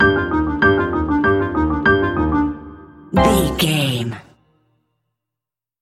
Uplifting
Aeolian/Minor
flute
oboe
strings
circus
goofy
comical
cheerful
perky
Light hearted
quirky